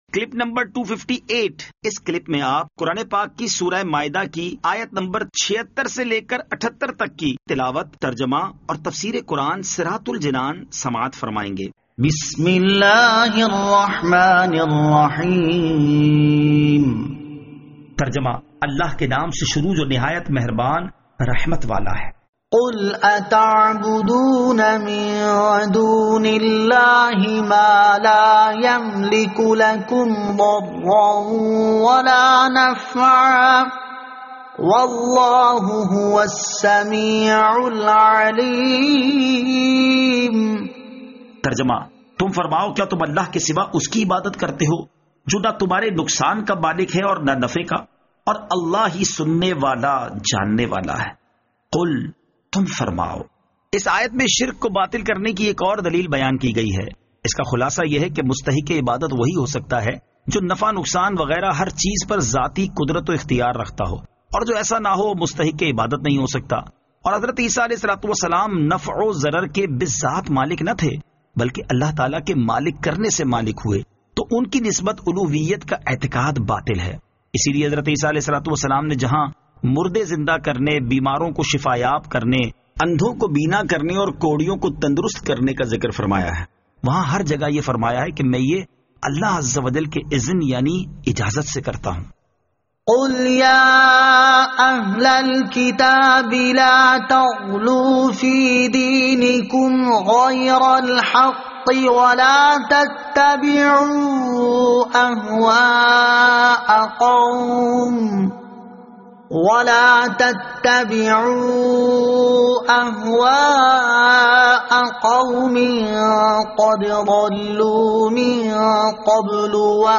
Surah Al-Maidah Ayat 76 To 78 Tilawat , Tarjama , Tafseer